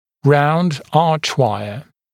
[raund ‘ɑːʧˌwaɪə][раунд ‘а:чˌуайэ]дуга круглого сечения, круглая дуга